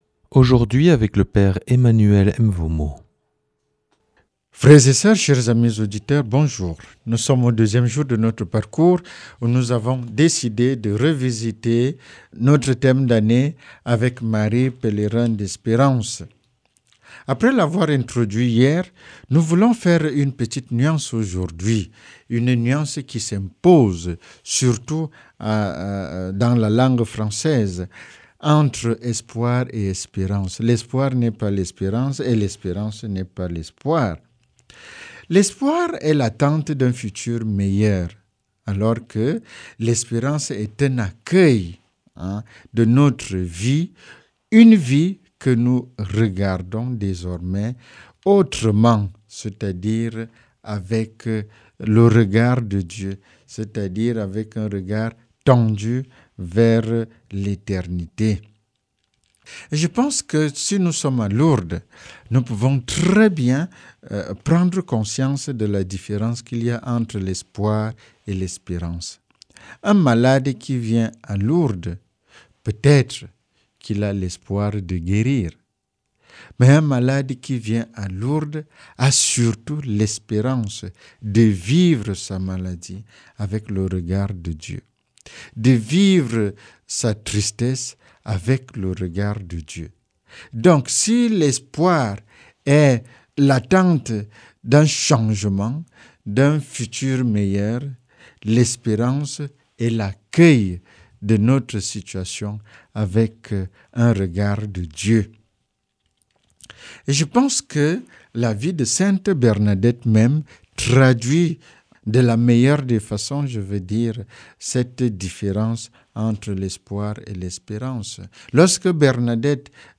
mardi 10 juin 2025 Enseignement Marial Durée 10 min